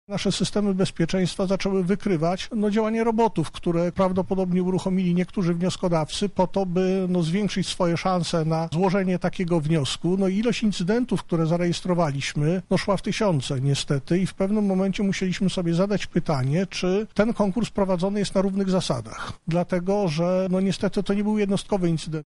Dyrektor LAWP Andrzej Sadłowski tłumaczy problemy z którymi mieli do czynienia w związku z konkursem oraz główny powód jego odwołania.